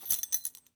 foley_keys_belt_metal_jingle_01.wav